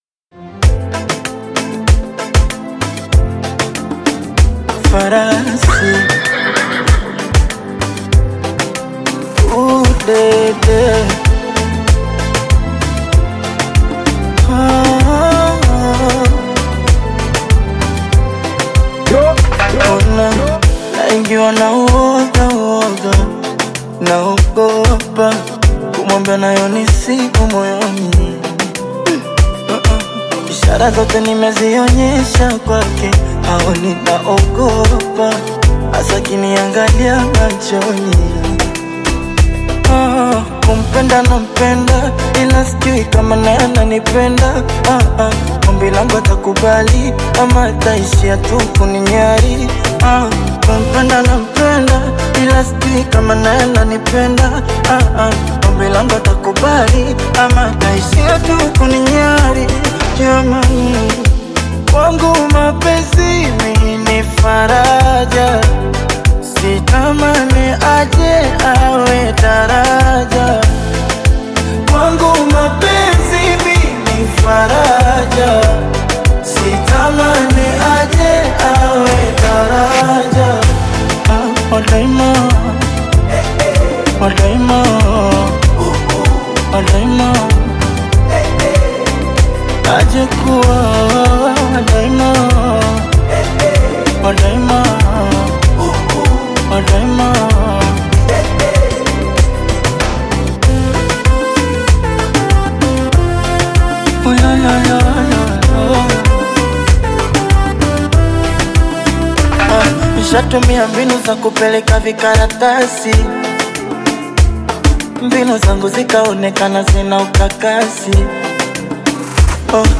Afro-Beat single